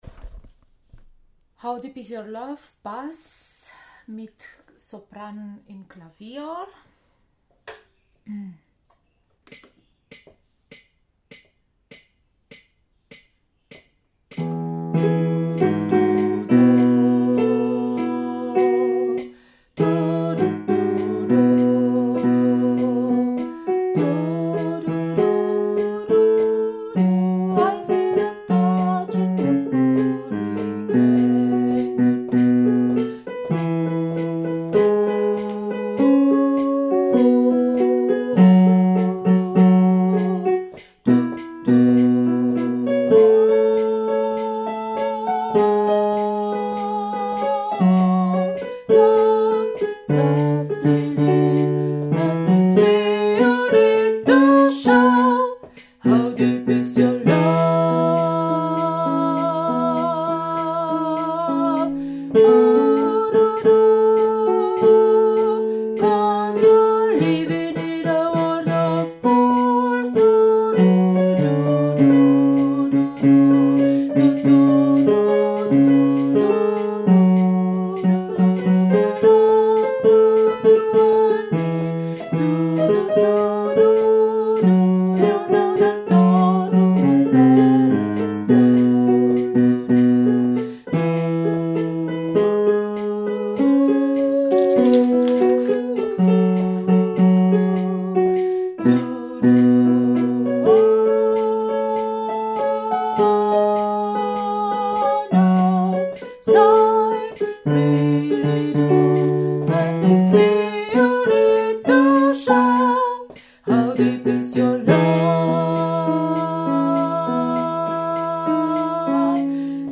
How deep is your love – Bass (mit Klavierbegleitung Sopran)